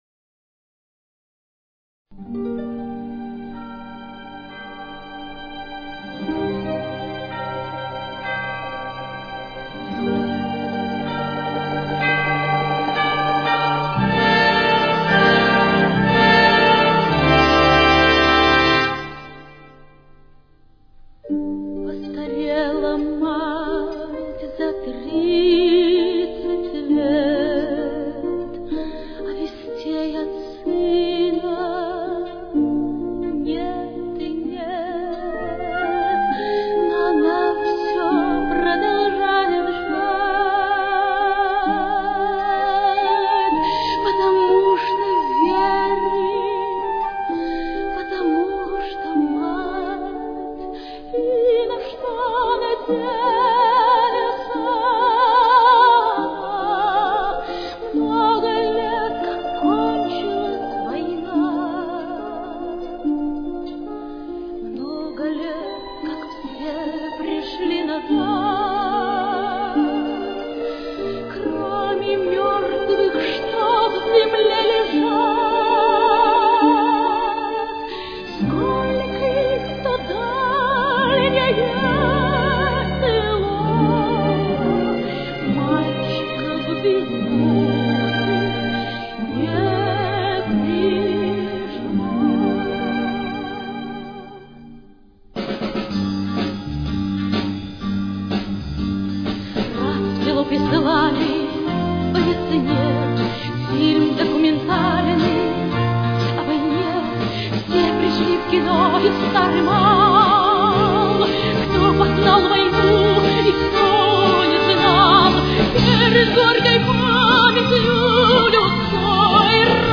российская певица, исполнительница романсов.
с очень низким качеством (16 – 32 кБит/с)